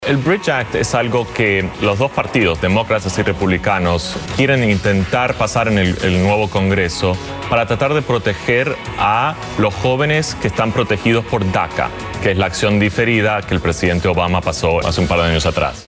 Abogado de inmigración